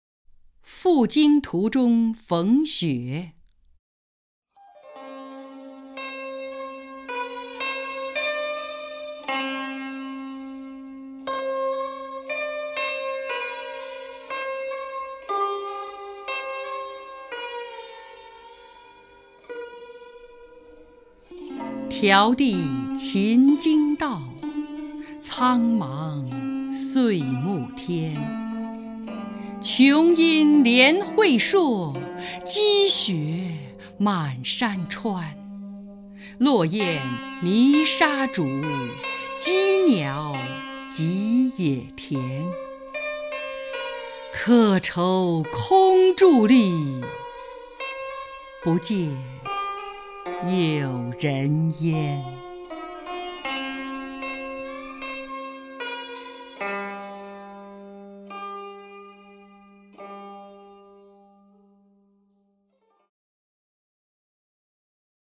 张筠英朗诵：《赴京途中逢雪》(（唐）孟浩然)
名家朗诵欣赏 张筠英 目录